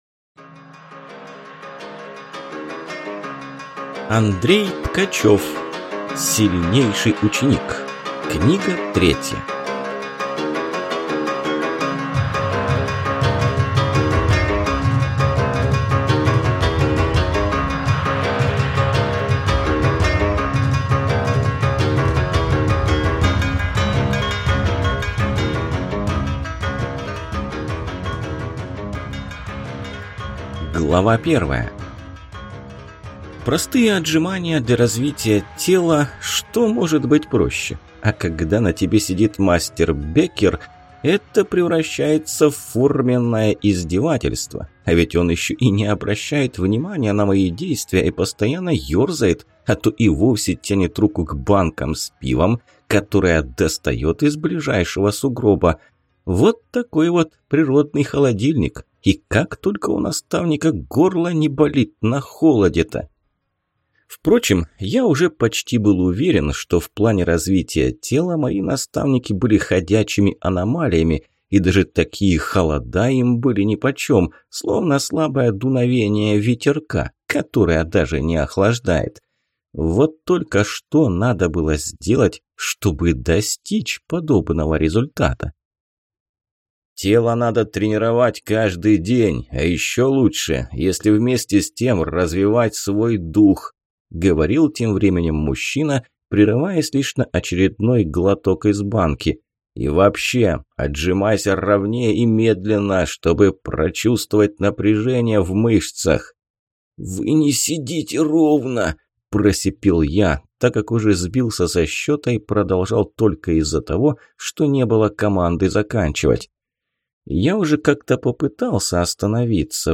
Аудиокнига Сильнейший ученик. Книга 3 | Библиотека аудиокниг
Прослушать и бесплатно скачать фрагмент аудиокниги